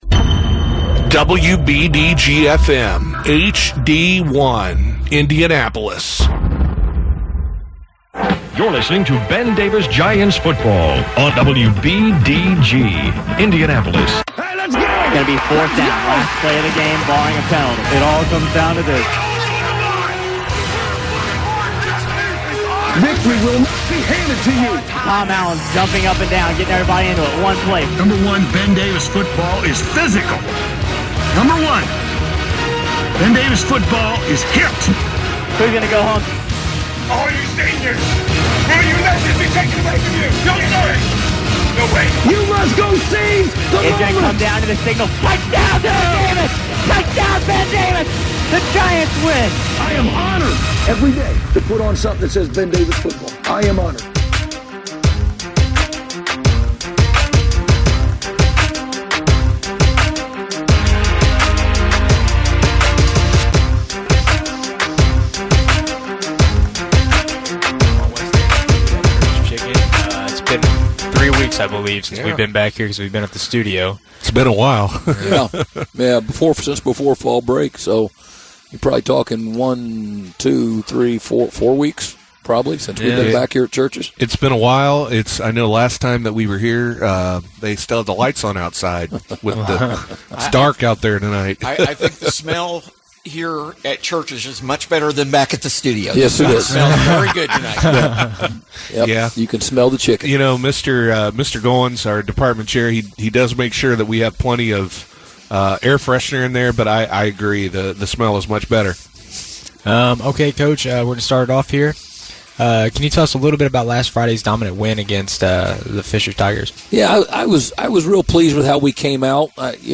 He also discusses the upcoming game against Avon for the Semi-State Championship. Recorded live at Church's Chicken on West 10th Street on November 14, 2017.